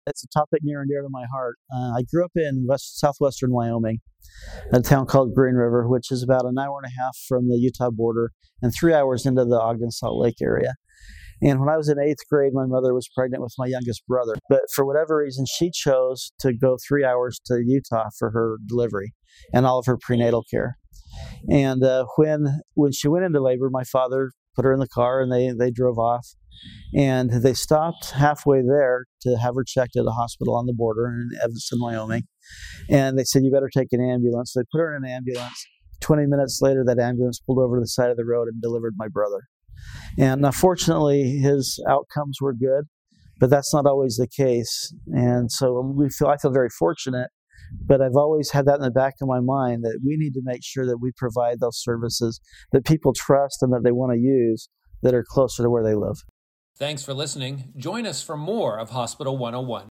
Leaders from Oregon’s hospital and health care community explain complex issues that impact the health care system, hospitals, and ultimately patients.